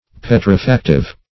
petrifactive - definition of petrifactive - synonyms, pronunciation, spelling from Free Dictionary
Petrifactive \Pet`ri*fac"tive\, a.